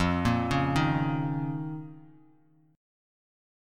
F7sus4#5 chord